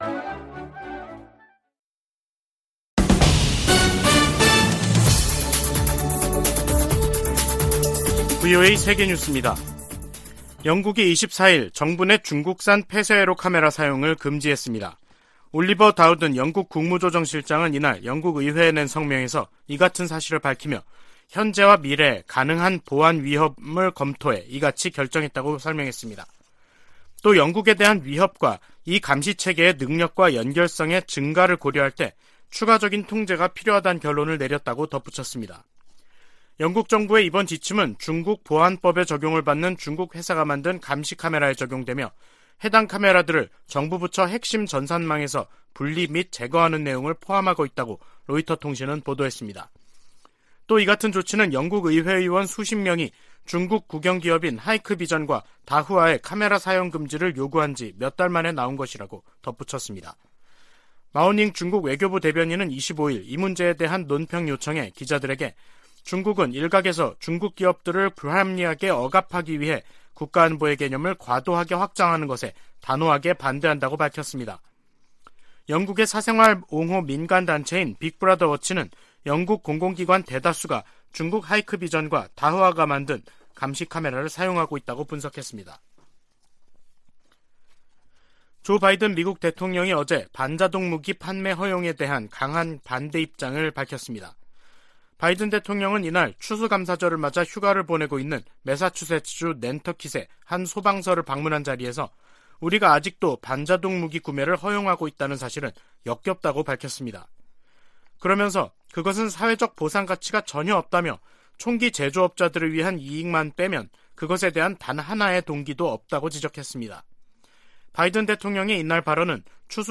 VOA 한국어 간판 뉴스 프로그램 '뉴스 투데이', 2022년 11월 25일 3부 방송입니다. 미국 의회에서 북한의 도발을 방조하는 ‘세컨더리 제재’등으로 중국에 책임을 물려야 한다는 요구가 거세지고 있습니다. 미국 고위 관리들이 최근 잇따라 북한 문제와 관련해 중국 역할론과 책임론을 거론하며 중국의 협력 의지를 시험하고 있다는 전문가 분석이 제기됐습니다.